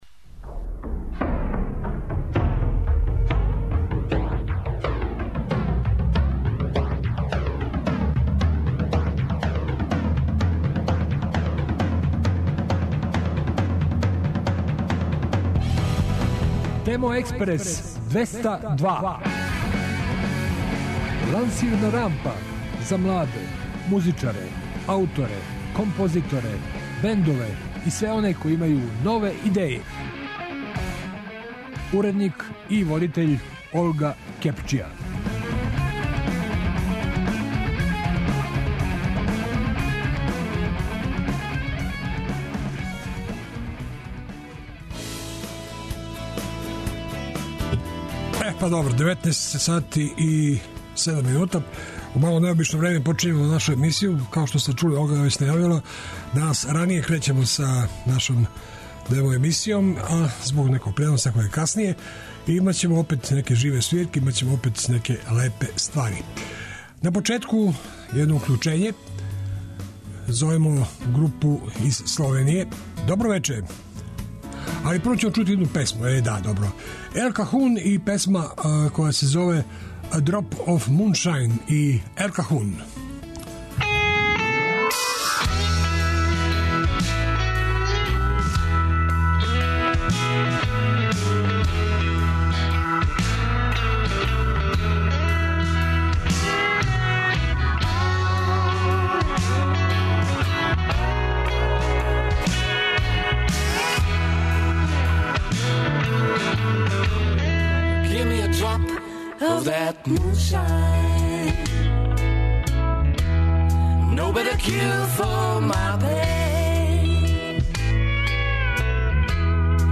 И ове недеље представљамо финалисте БУНТ РОК МАСТЕРСА - уживо ће вам се представити ДИНГОСПО ДАЛИ И КОРА из Београда. Упознаћемо вас и са преосталим полуфиналистима које до сад нисте били у прилици да чујете: